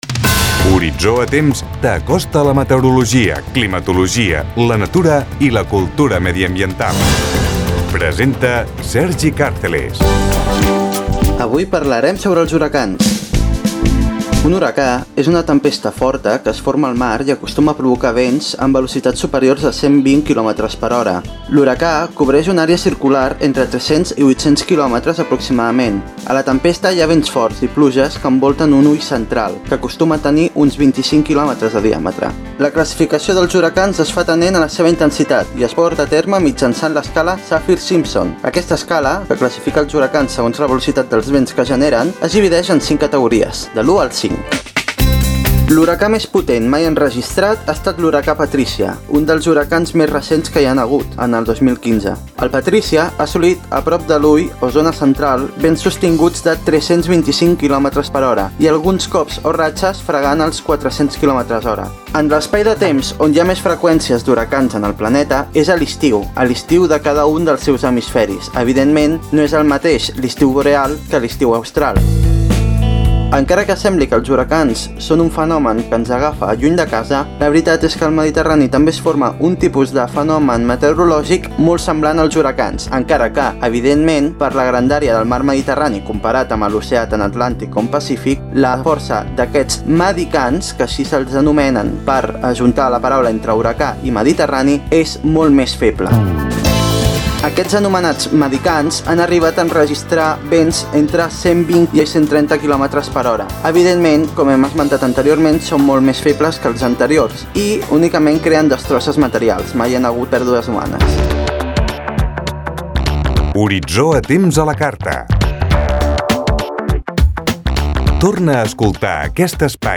Careta i espai dedicat als huracans
Divulgació